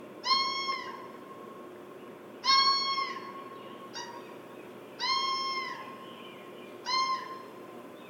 Red fox
(Vulpes valpes)
Red-Fox-edit.mp3